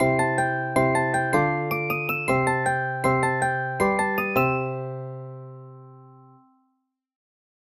今となっては「ジングルにでも使えるかな？」と思ったため、素材として公開することになった。